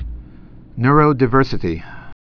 (nrō-dĭ-vûrsə-tē, -dī-, nyr-)